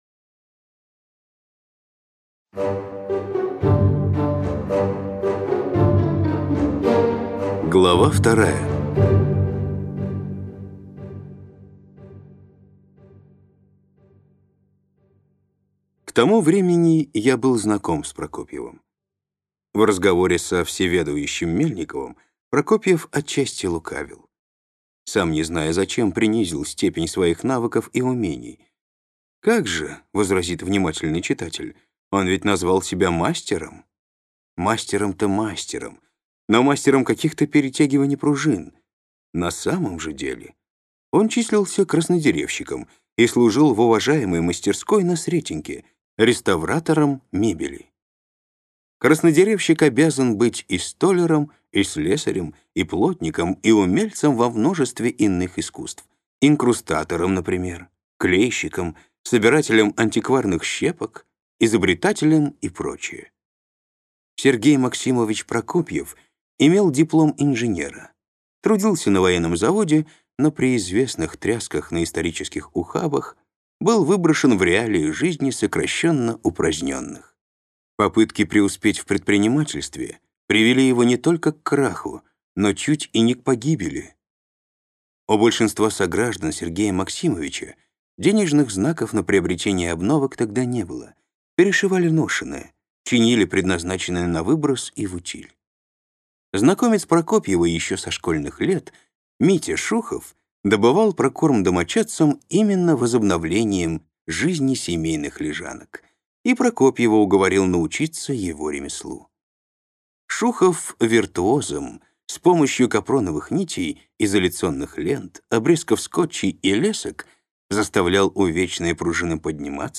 Aудиокнига Камергерский переулок